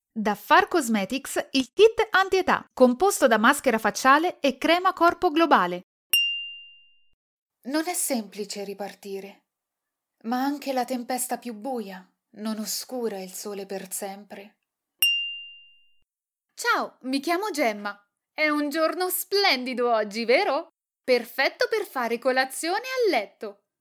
电影角色【多角色 】